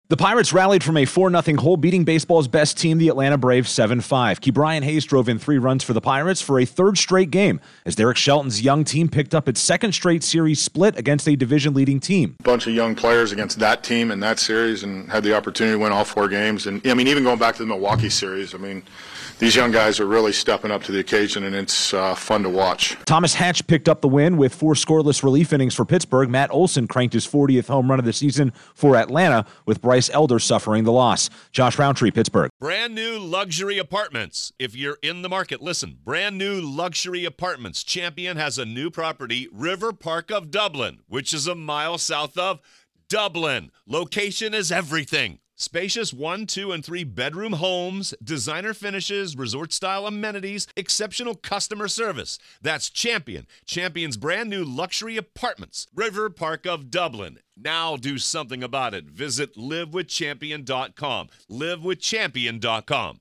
The Pirates hold their own against a division leader again. Correspondent